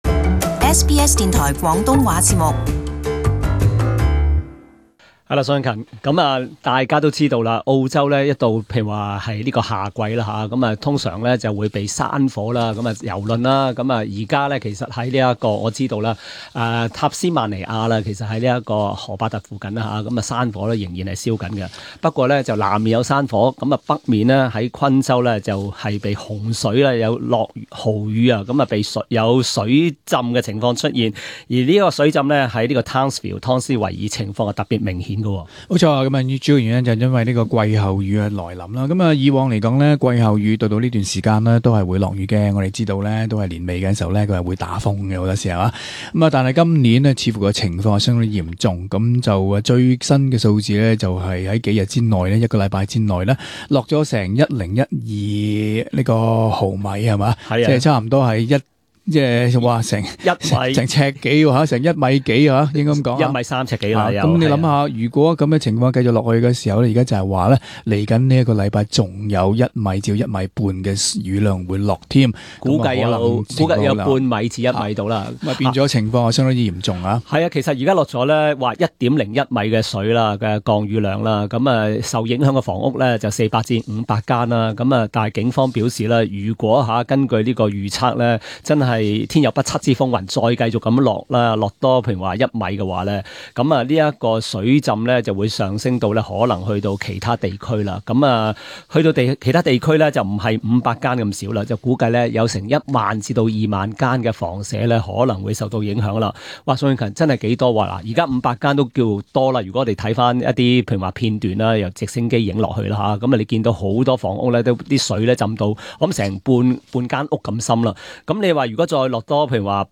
Source: AAP SBS广东话播客 View Podcast Series Follow and Subscribe Apple Podcasts YouTube Spotify Download (11.66MB) Download the SBS Audio app Available on iOS and Android 汤斯维尔最近一周豪雨成灾，降雨量超过 1 米。 但由于 Ross River Dam 的储水量，是原来设计可支持的储水量的两倍多，所以政府当局决定昨晚 8 时许开始把河坝闸口全面打开，全面排洪。